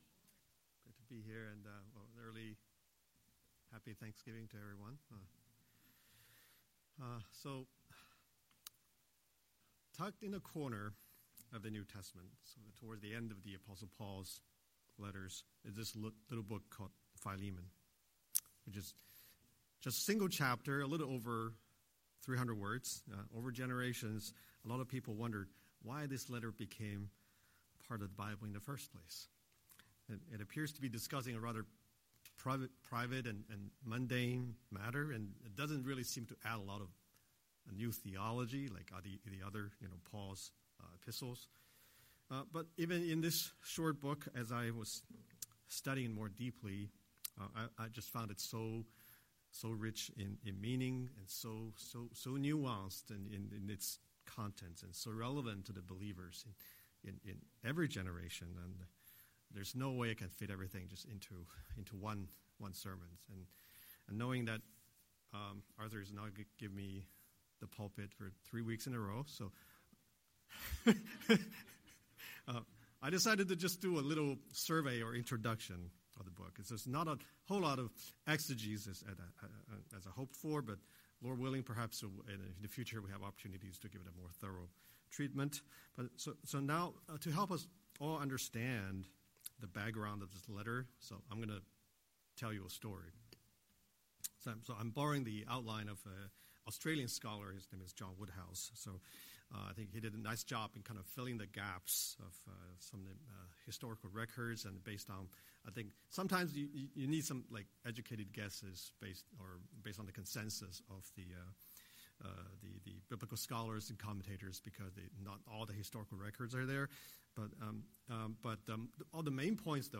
Scripture: Philemon 1:1–21 Series: Sunday Sermon